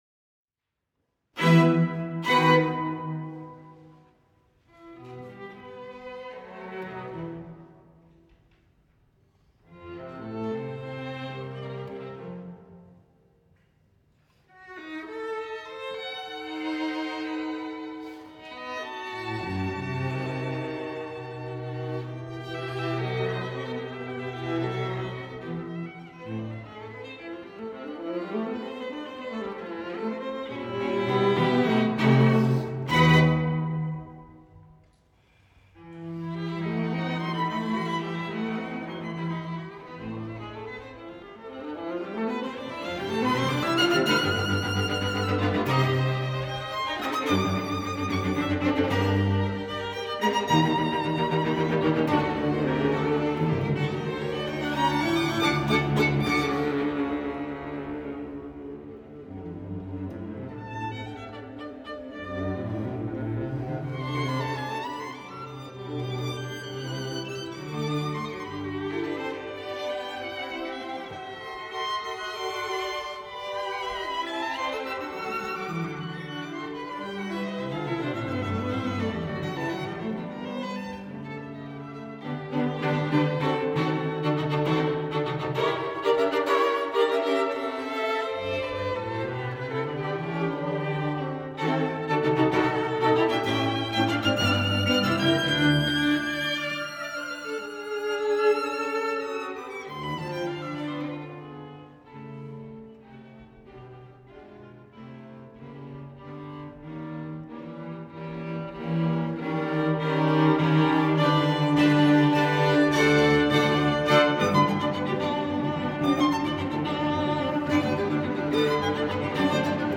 Complete Performance *#343323
Performer Pages Borromeo String Quartet (String Quartet) Publisher Info.